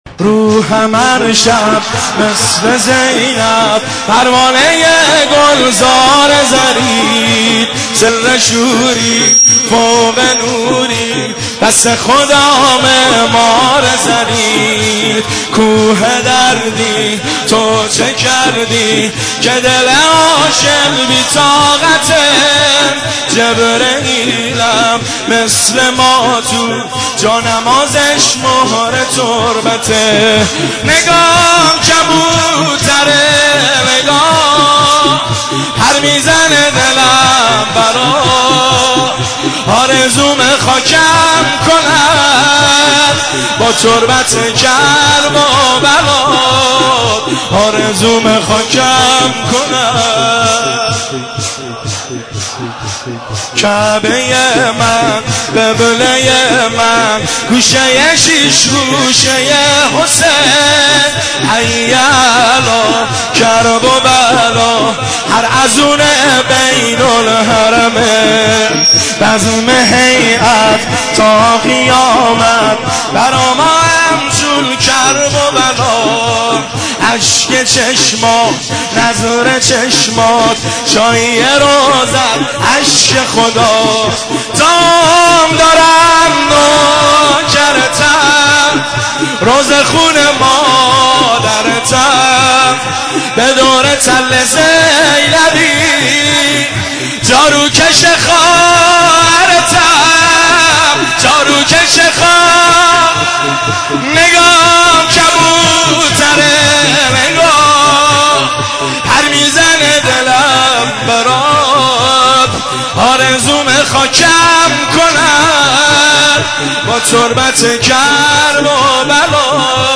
مداحی روحم هر شب(شور)
هیئت خادم بین الحرمین